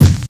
Kick_22.wav